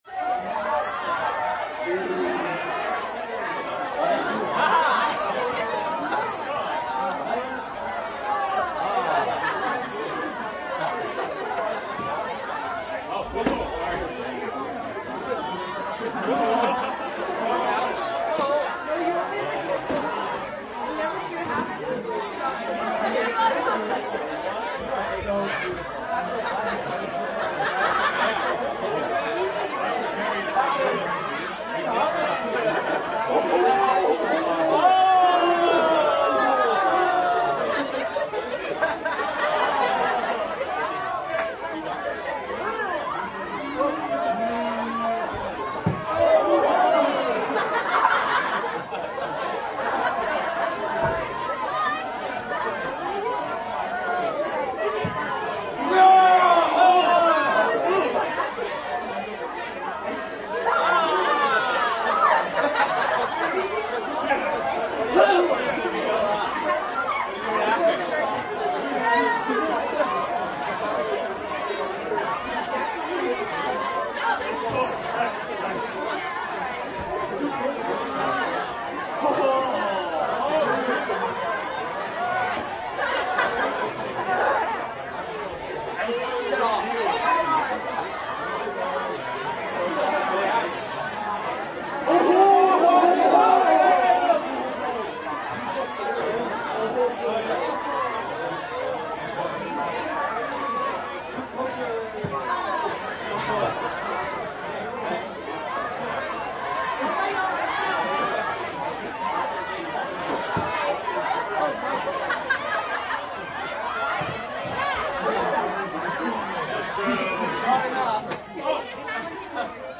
Звуки вечеринки с музыкой и голосами и криками людей в квартире и на улице слушать онлайн.
1. Звуки шумной вечеринки без музыки